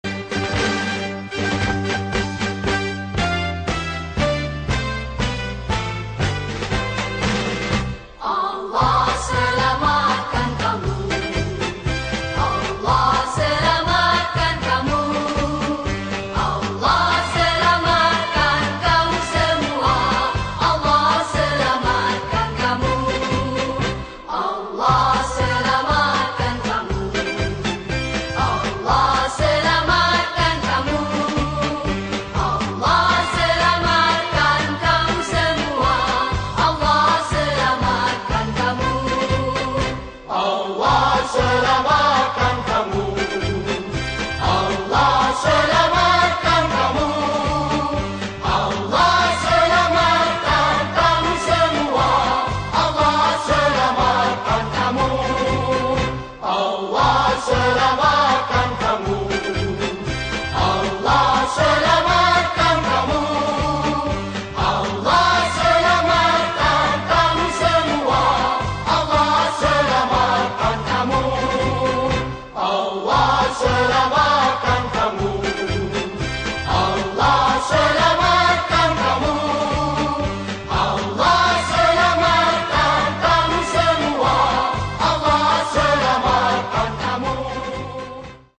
Malay Song